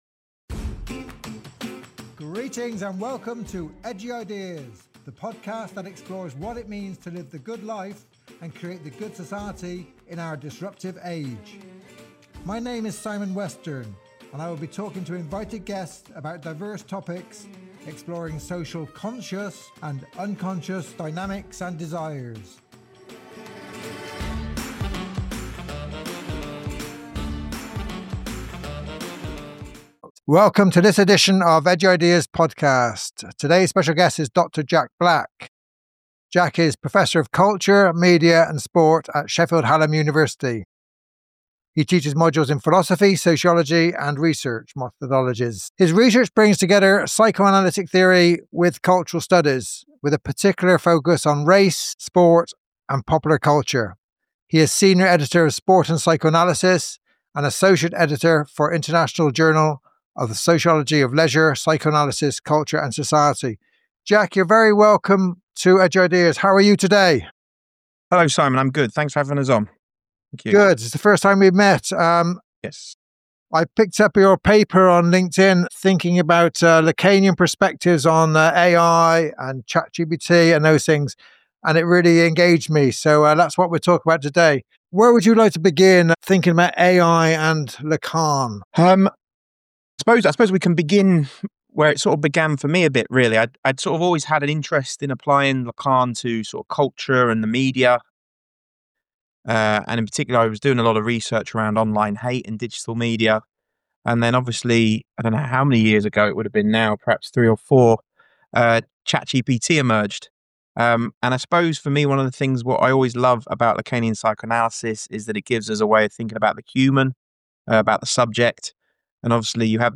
This is a conversation about desire, discourse, power and the fantasies we project onto machines.